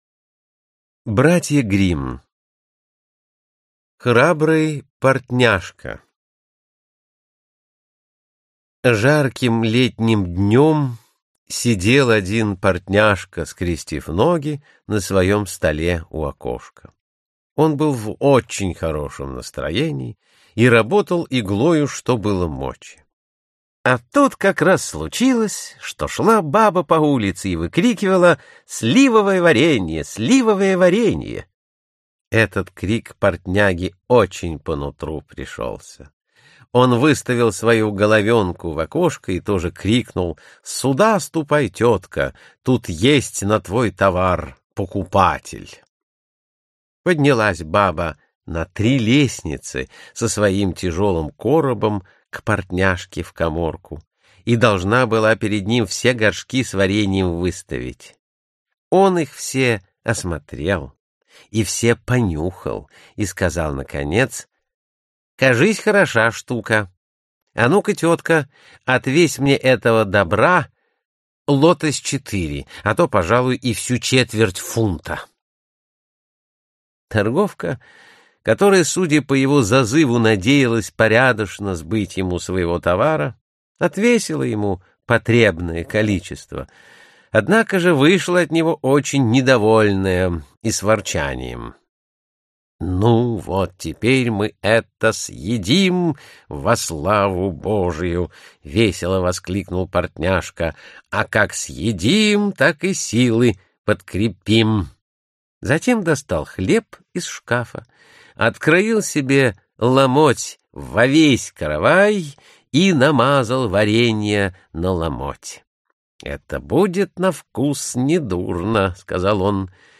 Аудиокнига 22 знаменитые сказки. По страницам любимых книг | Библиотека аудиокниг